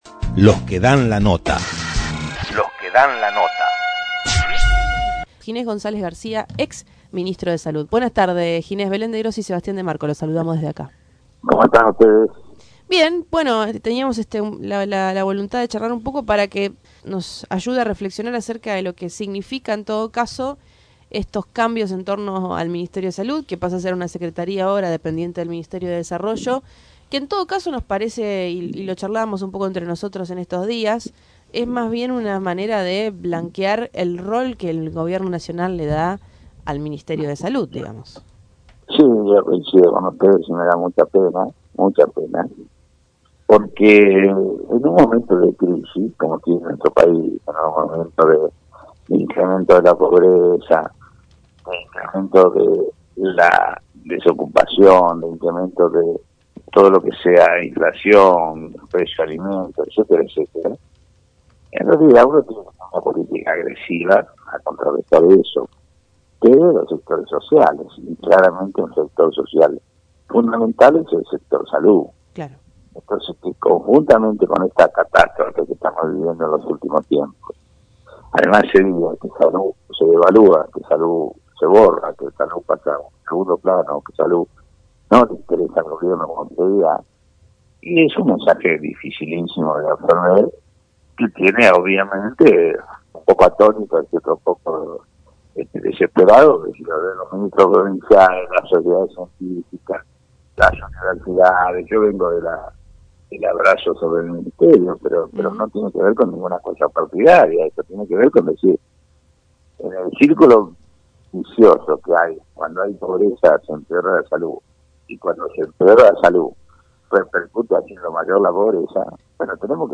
Ginés González García, ex-Ministro de Salud de la Nación, en diálogo con Tren Urbano sobre la degradación del Ministerio de Salud al rango de Secretaría de estado.